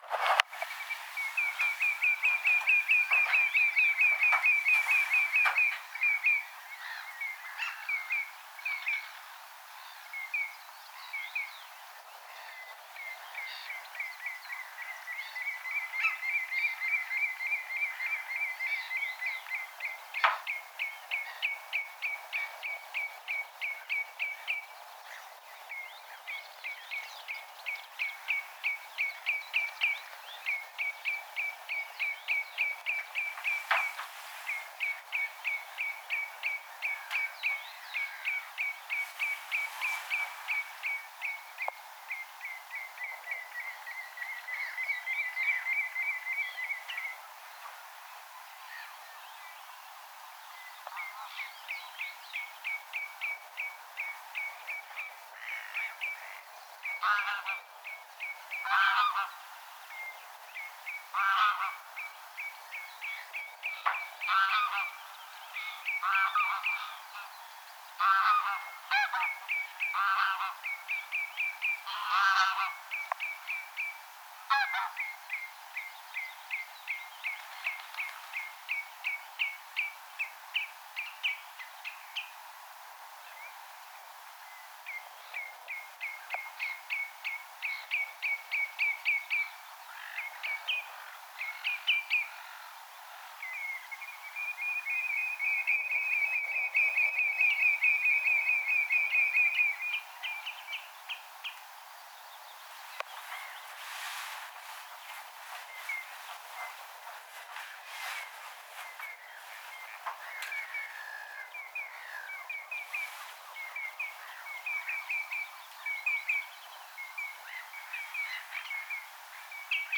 Satoi - välillä aika kovaa.
punajalkaviklot ääntelivät paljon,
kun olin lintutornissa, 2
punajalkaviklot_aantelivat_jatkuvasti_kun_olin_lintutornissa_2.mp3